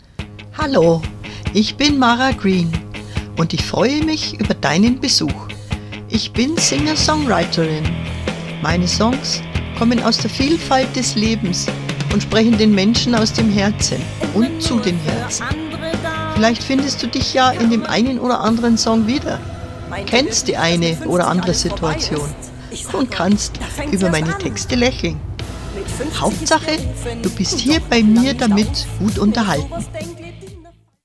Hallo-_mit-musik-Untermalung.mp3